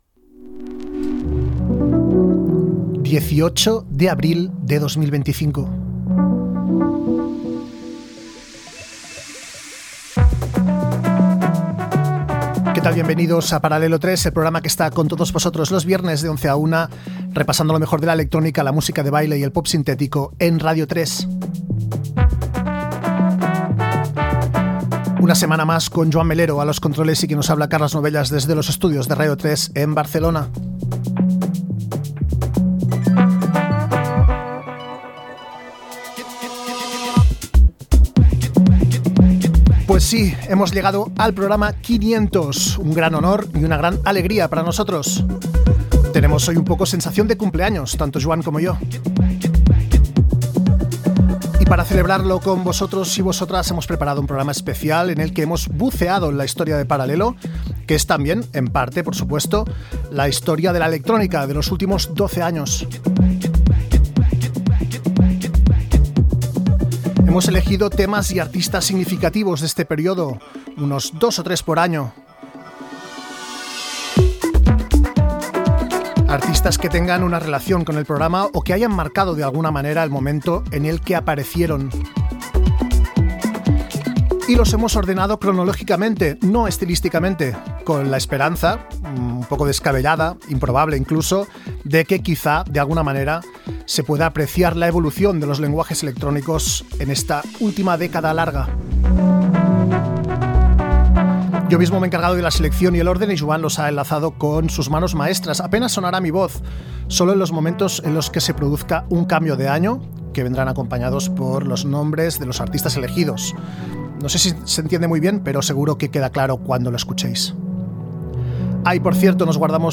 Data, presentació del programa 500 i tema musical Gènere radiofònic Musical